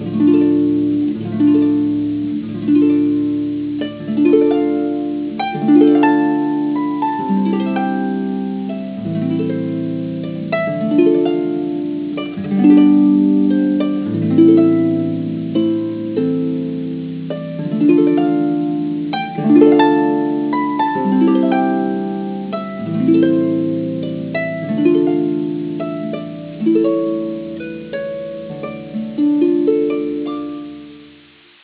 Relaxing and inspiring.